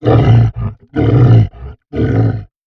MONSTERS_CREATURES
MONSTER_Exhausted_09_mono.wav